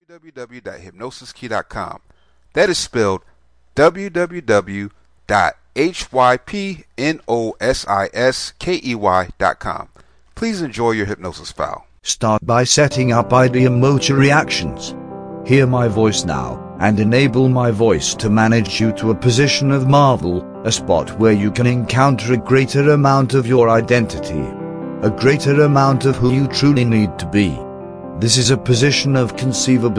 Situps Weight Loss Hypnosis Mp3